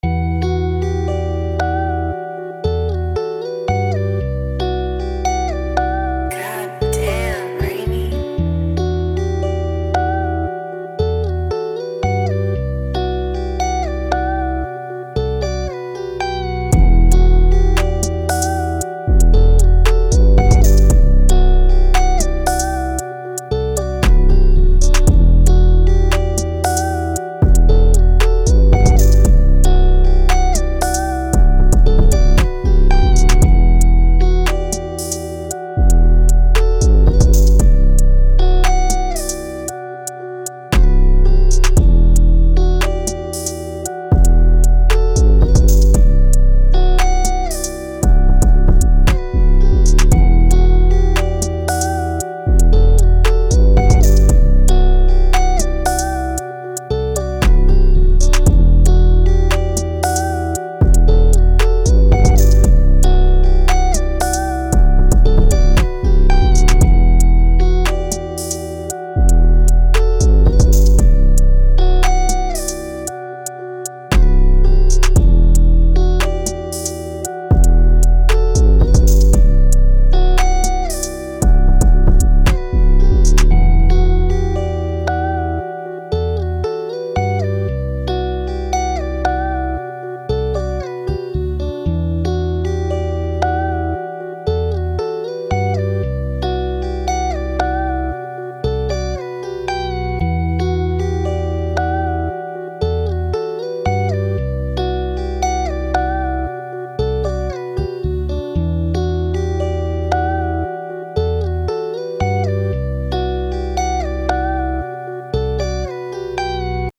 115BPM (RAP/GUITAR/RNB) CO